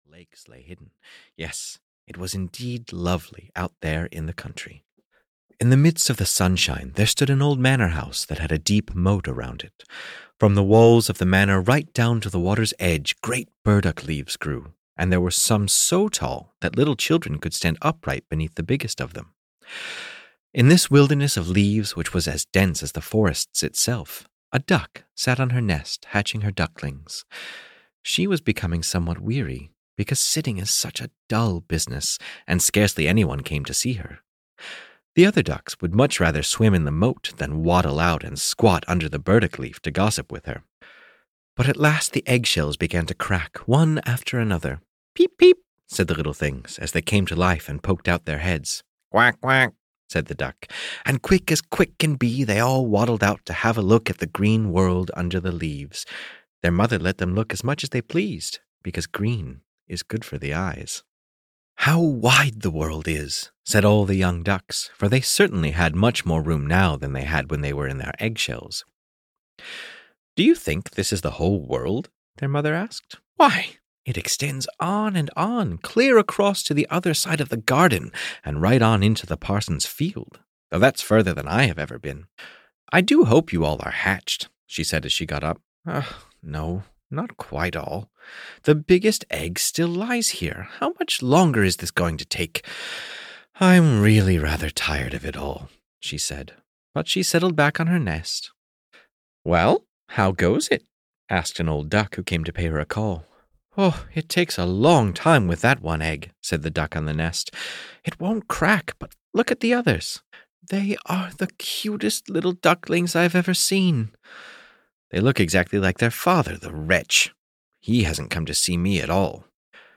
The Ugly Duckling (EN) audiokniha
Ukázka z knihy